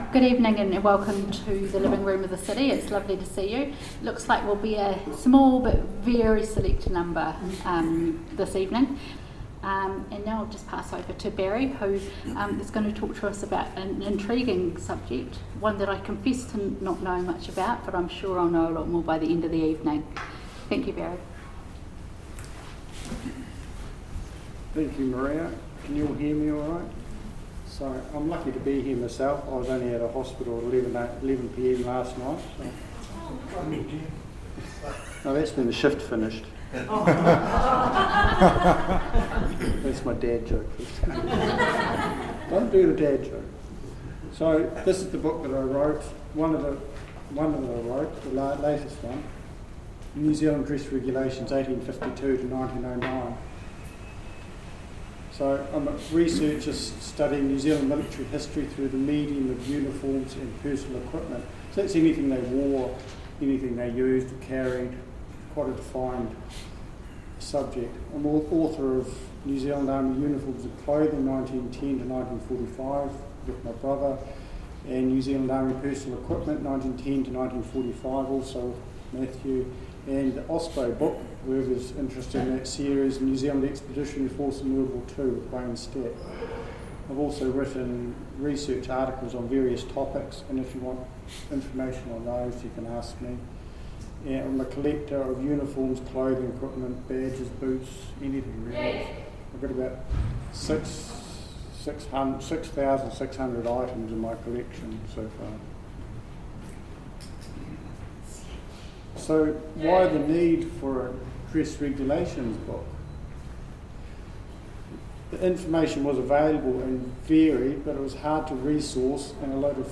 The talk will be based on the book New Zealand Dress Regulations 1852-1909 and will help to fill the void in our understanding of uniforms and accoutrements of the period. The public are invited to bring along items and photos from the volunteer period for discussion and identification.